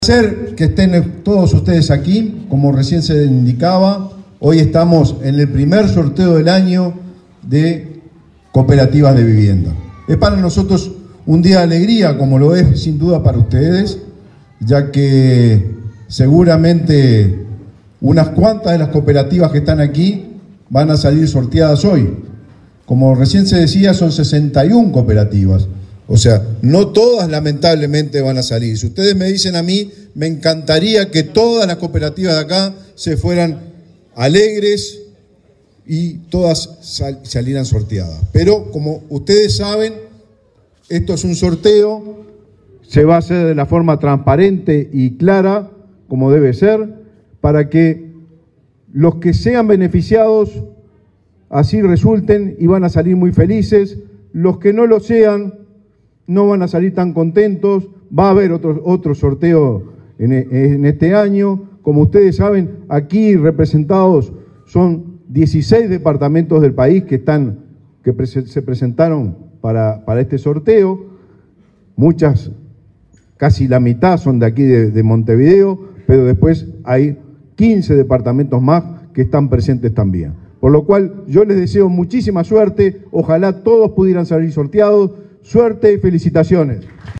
Palabras del ministro de Vivienda, Raúl Lozano
El ministro de Vivienda, Raúl Lozano, participó, este jueves 8 en la sede de su cartera, del primer sorteo de este año, de cupos para construcción de